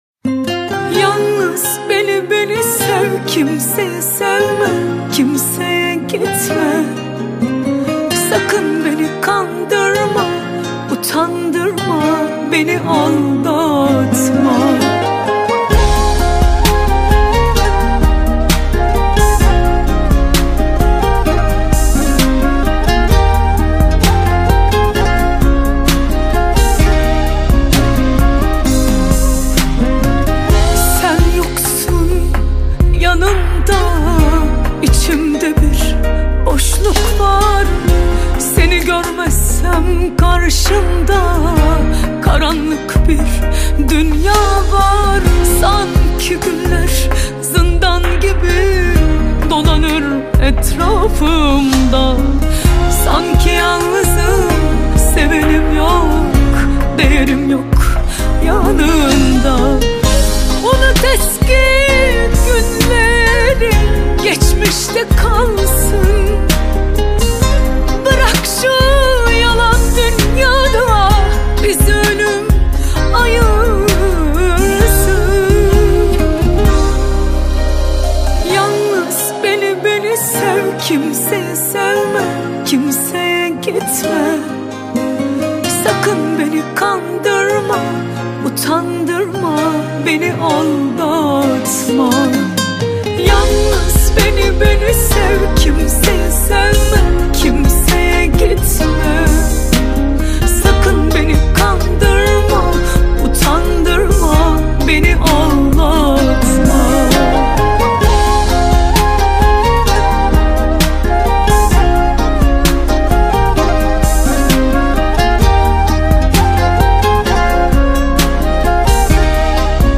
خواننده زن